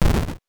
8 bits Elements / explosion
explosion_7.wav